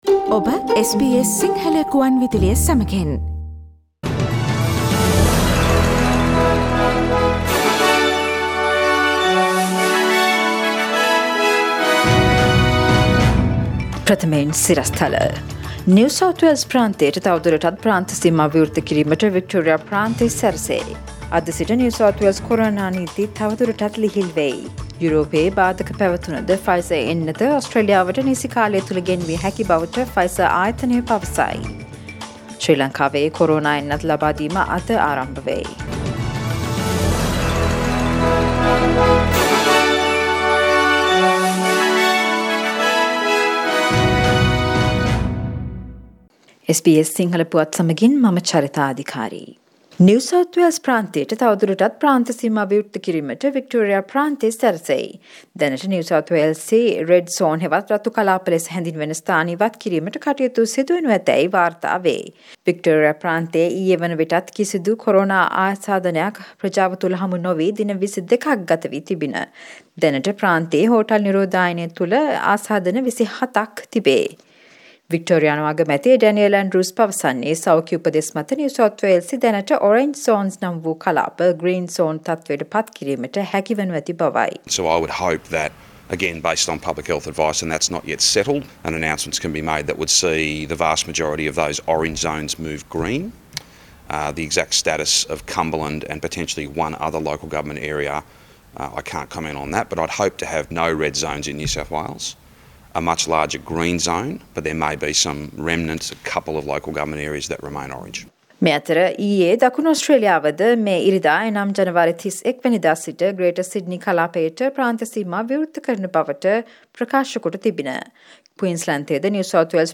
Today’s news bulletin of SBS Sinhala radio – Friday 29 January